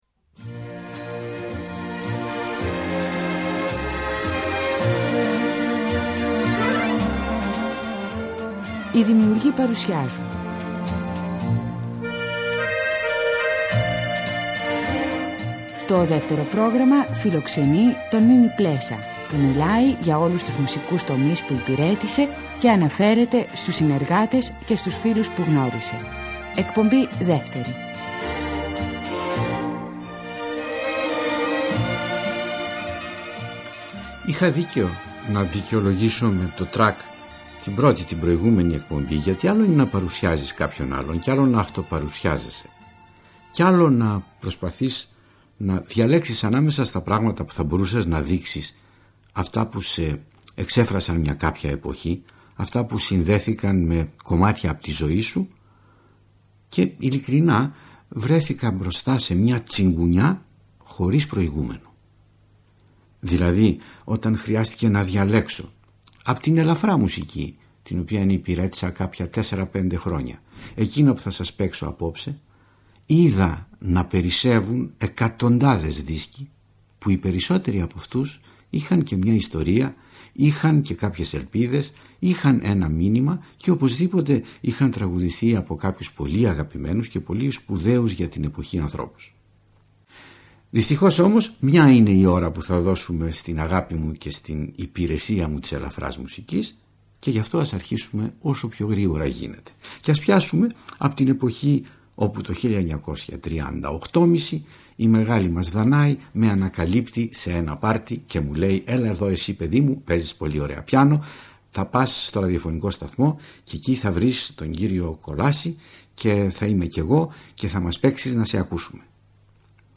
Στις εκπομπές αυτές, ο μεγάλος συνθέτης αυτοβιογραφείται, χωρίζοντας την έως τότε πορεία του στη μουσική, σε είδη και περιόδους, διανθίζοντας τις αφηγήσεις του με γνωστά τραγούδια, αλλά και με σπάνια ηχητικά ντοκουμέντα.